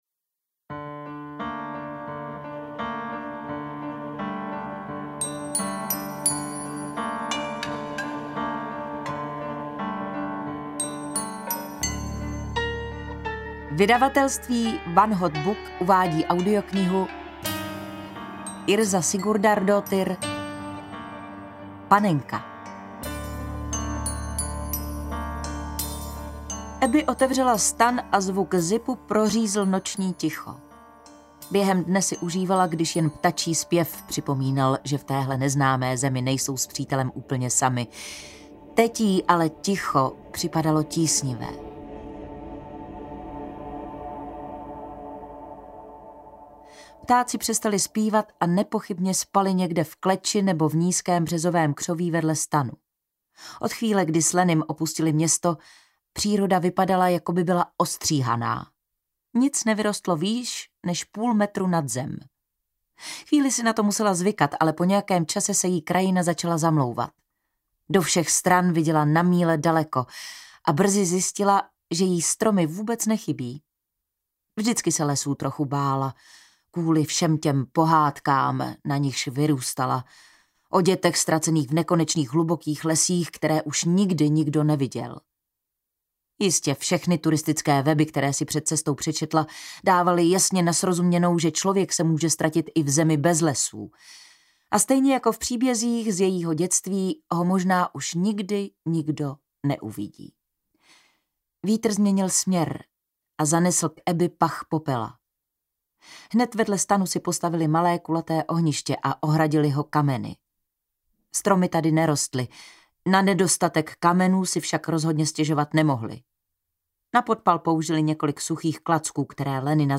Panenka audiokniha
Ukázka z knihy